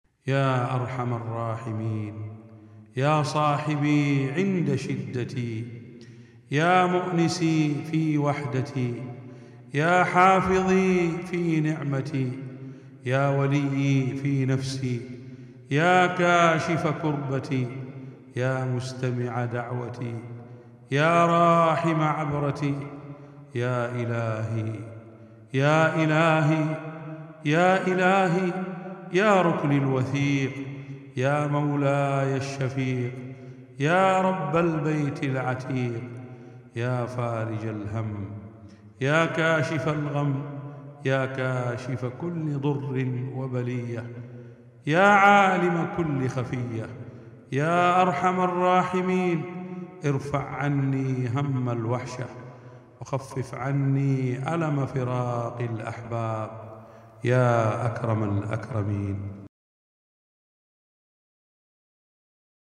دعاء مؤثر مليء بالاستغاثة والمناجاة، يتوجه فيه الداعي إلى الله بقلب منكسر طالباً رفع الهم وكشف الضر ودفع الوحشة. يعبر النص عن حالة من الألم والشوق مع يقين بالرحمة الإلهية والعون القريب.